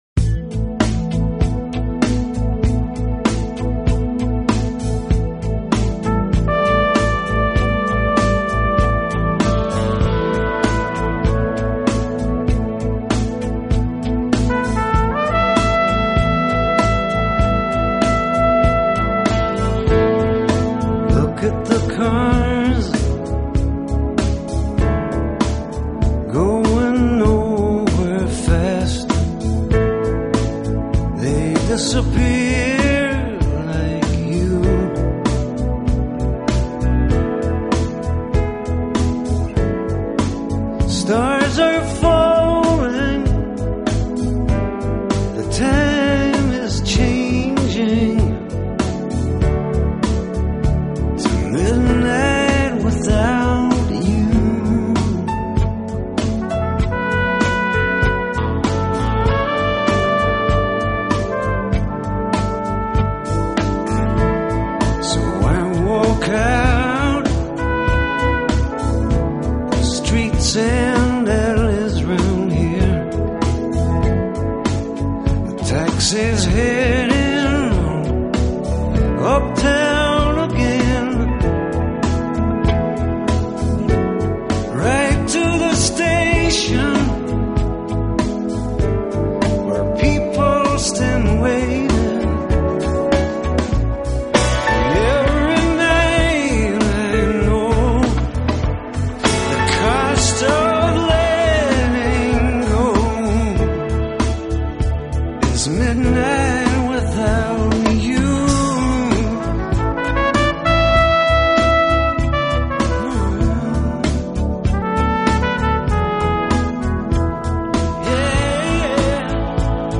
音乐风格：Jazz